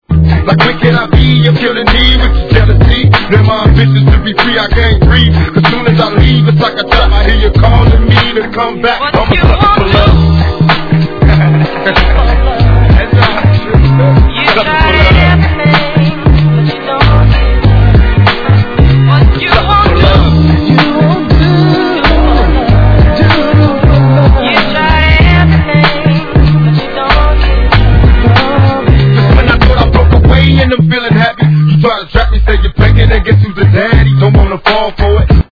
Tag       DRE FAMILY WEST COAST